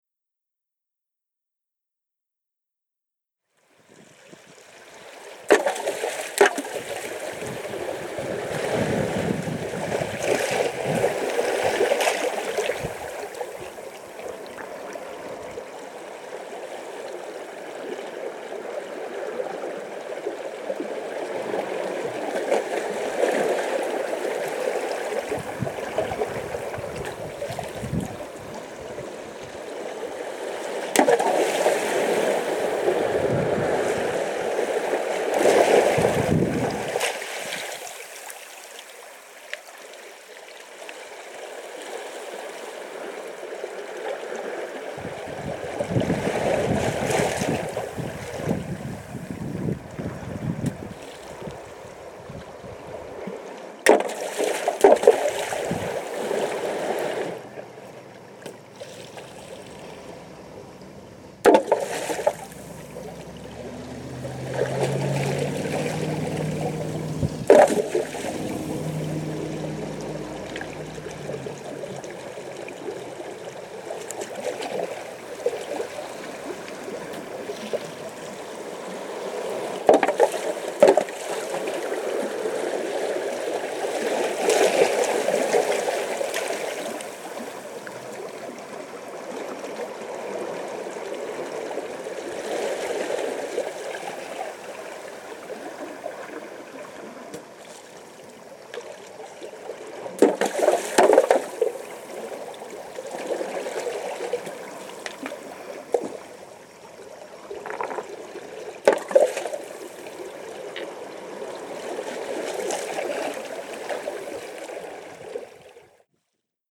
several performers at different places
Play the water with the stones